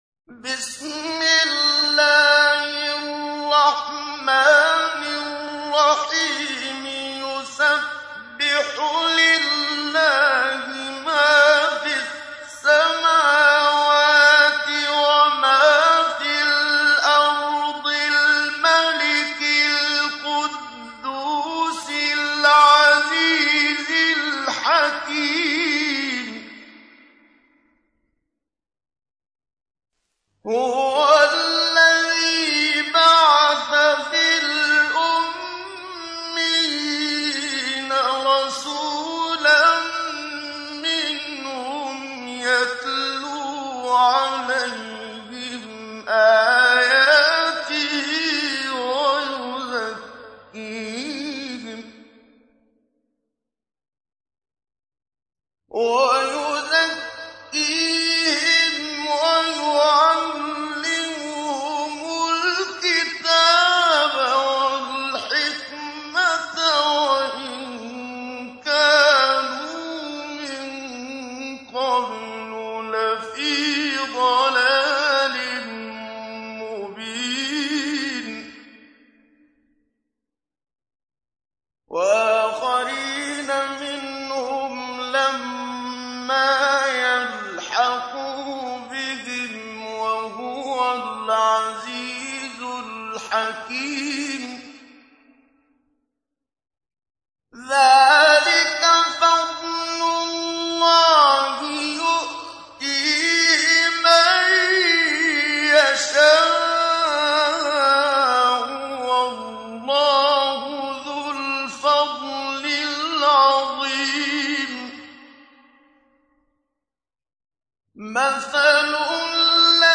تحميل : 62. سورة الجمعة / القارئ محمد صديق المنشاوي / القرآن الكريم / موقع يا حسين